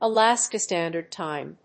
アクセントAláska (Stándard) Tìme